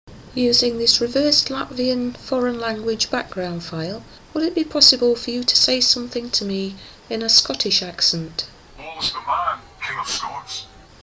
Scots Accent – The Amazing Portal
In this experiment I used my usual reverse Latvian background file. I asked if it were possible for our Communicators to reply in a Scot’s accent – we have received many different accents over the past 5 years.